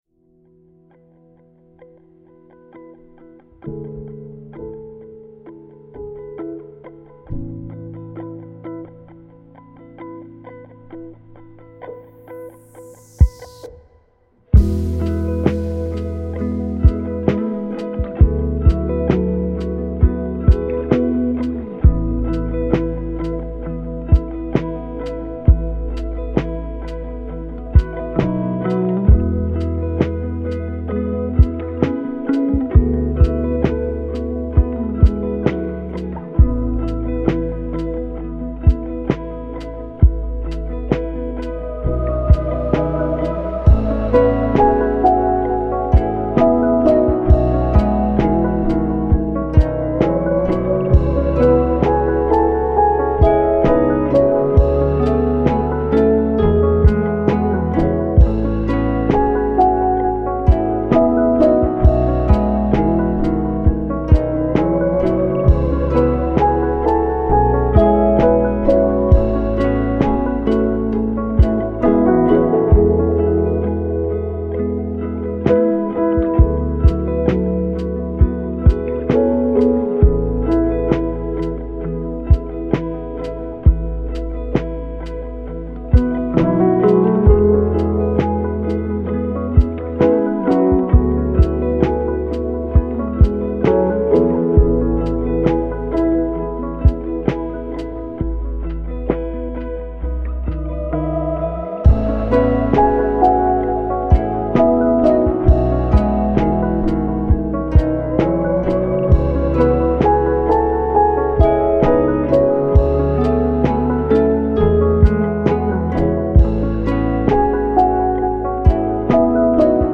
پادکست : تک آهنگ
دسته : پاپ